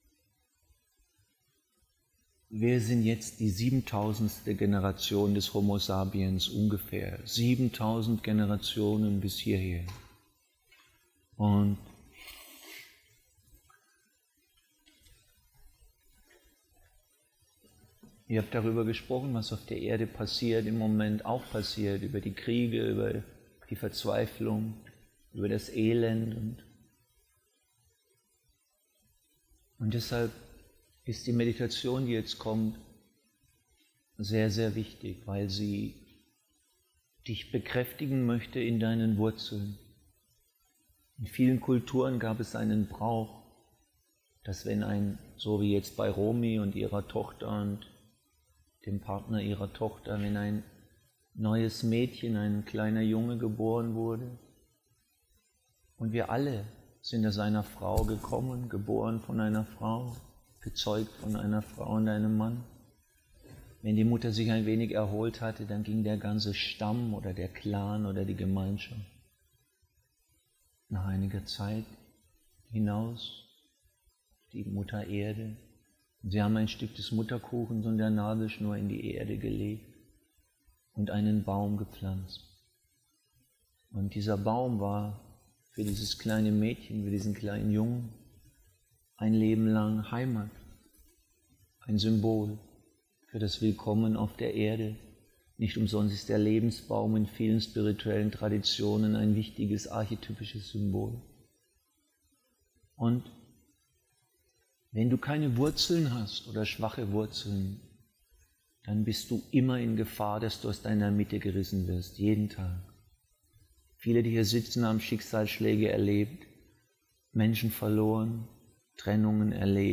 In diesem Audio-Teaching erfährst du, wie wichtig es ist, dich mit deinen Wurzeln zu verbinden. (Live-Mitschnitt aus der 3-jährigen Ausbildung Liebe & Erfolg/IKPT).